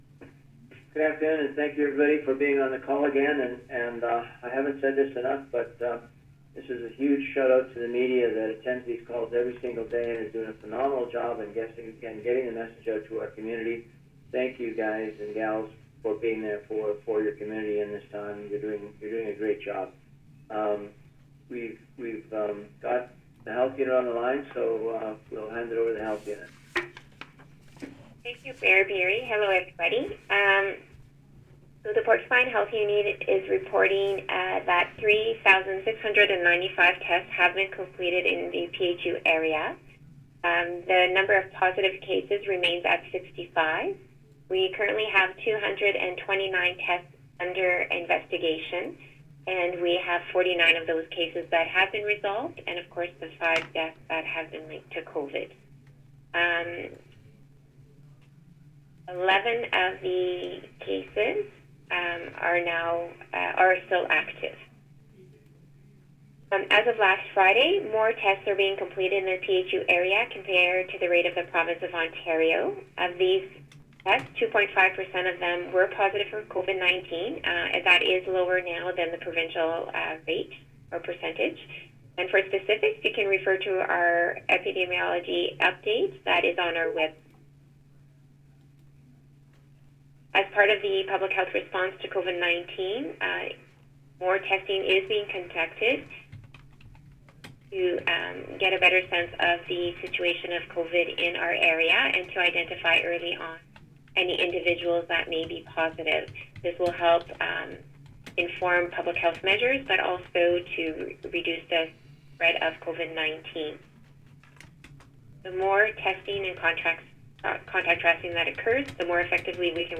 Here is raw audio of today’s call: